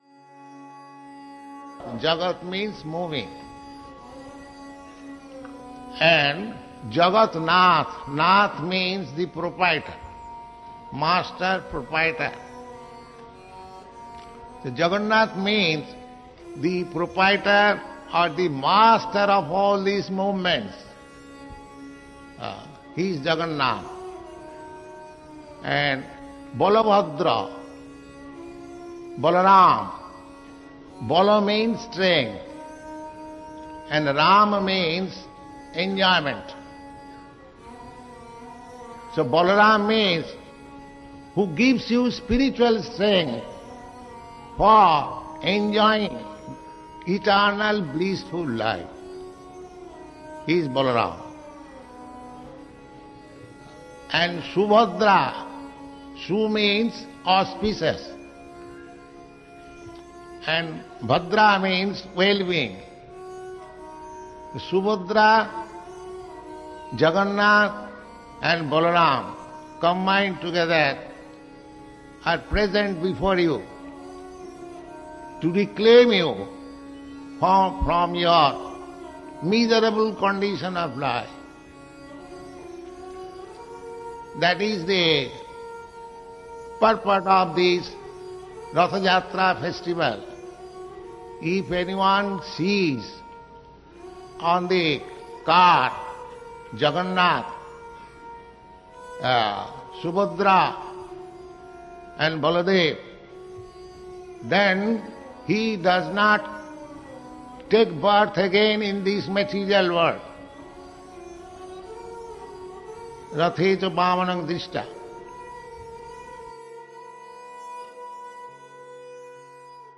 (700705 - Lecture Festival Ratha-yatra - San Francisco)